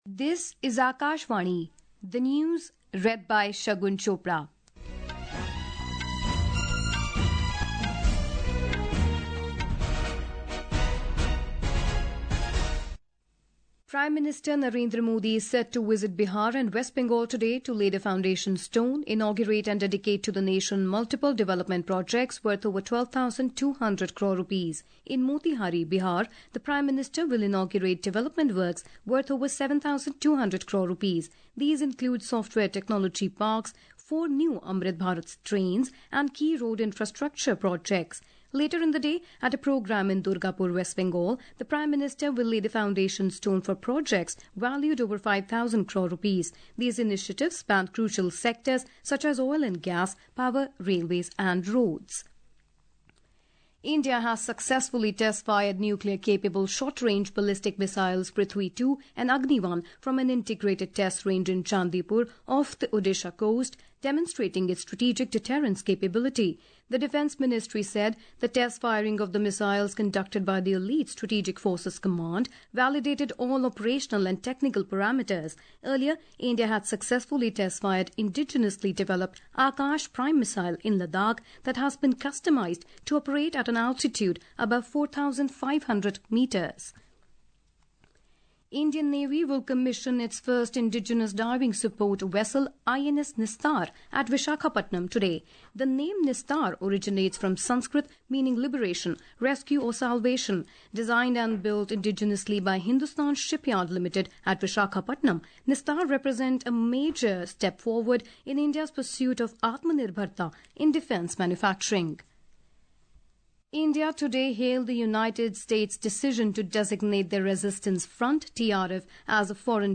Hourly News
Hourly News | English